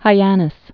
(hī-ănĭs)